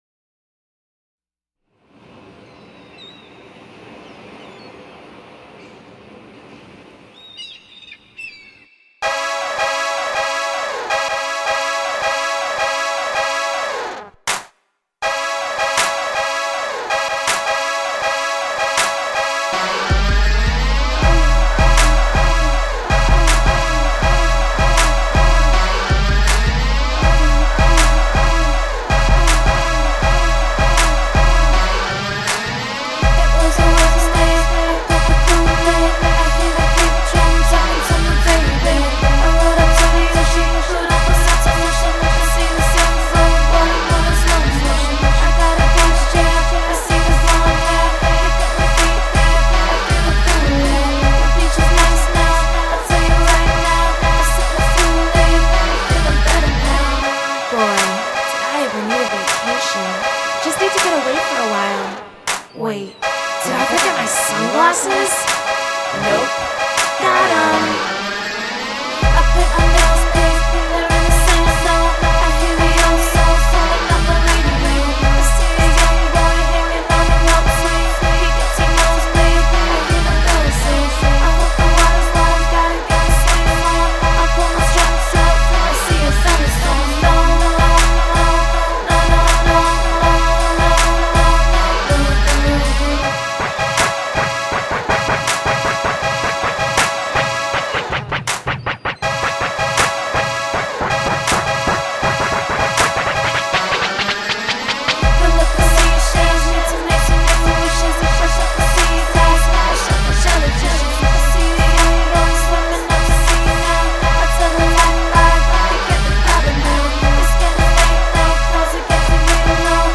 fusing searing guitars, fractured vocals and deep beats.